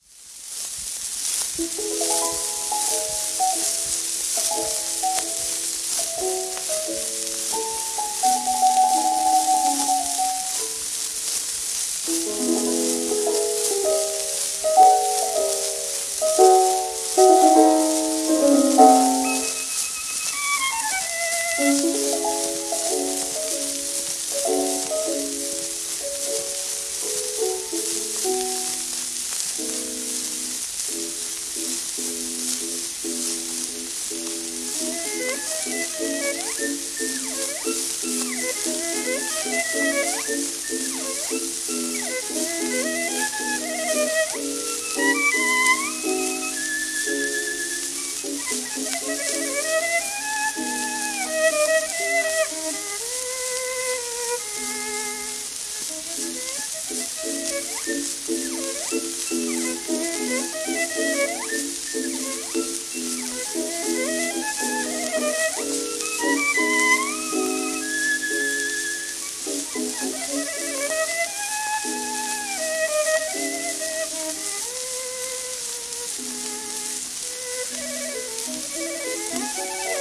12インチ片面盤
1921年録音
旧 旧吹込みの略、電気録音以前の機械式録音盤（ラッパ吹込み）